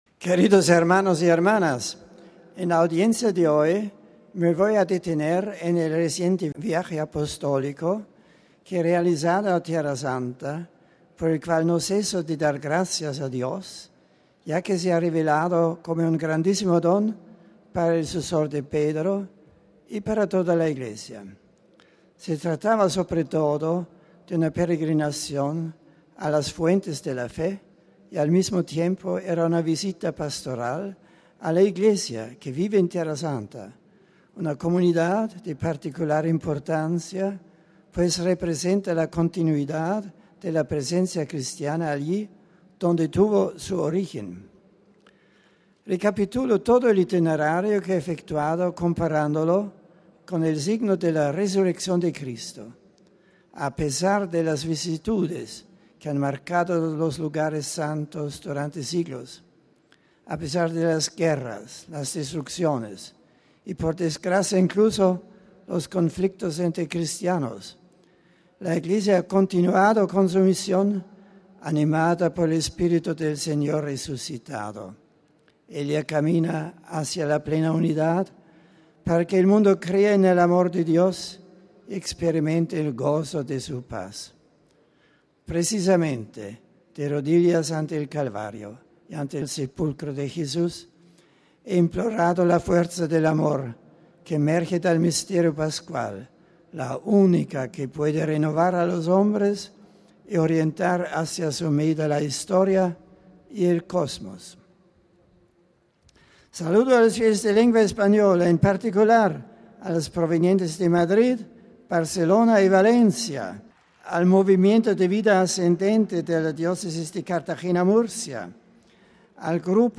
Éste ha sido el resumen que de su catequesis ha hecho en español el Santo padre sobre su peregrinación a Tierra Santa y saludando a los numerosos fieles de habla hispana, presentes en la Plaza de San Pedro: RealAudio